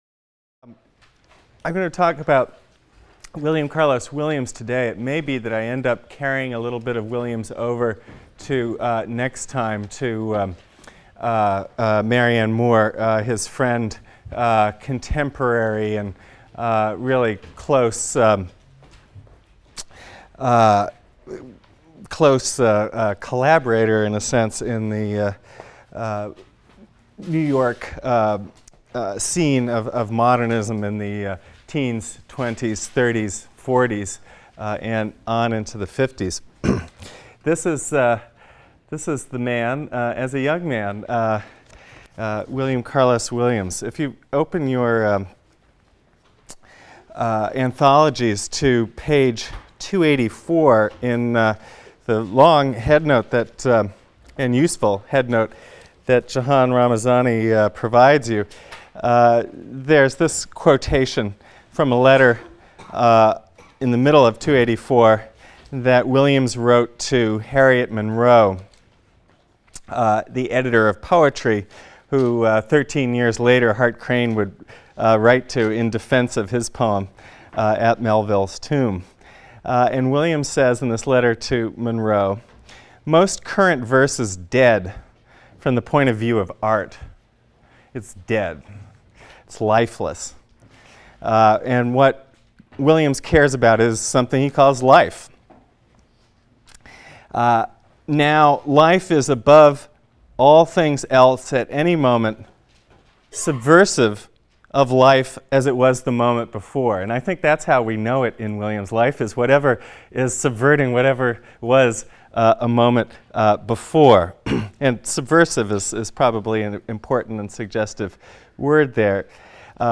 ENGL 310 - Lecture 16 - William Carlos Williams | Open Yale Courses